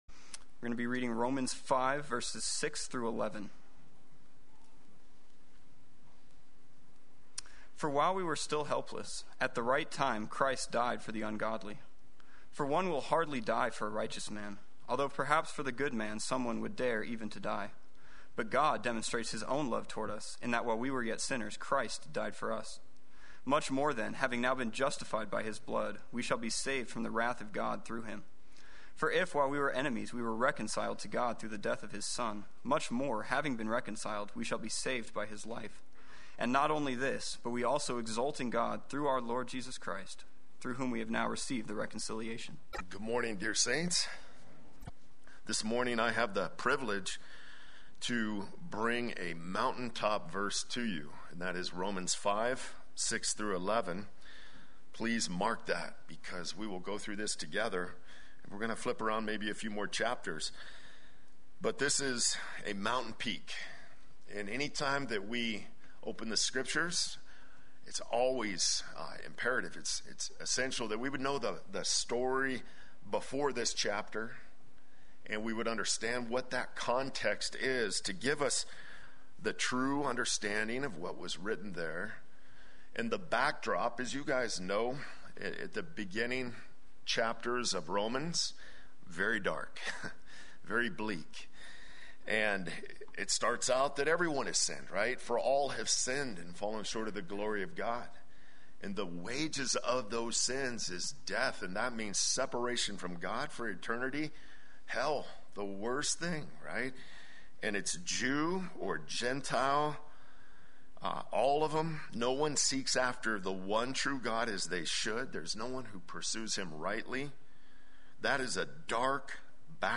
Play Sermon Get HCF Teaching Automatically.
Secure in God’s Love Sunday Worship